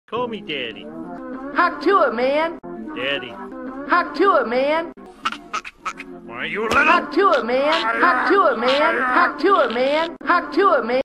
The Hawk tuah man sound button is from our meme soundboard library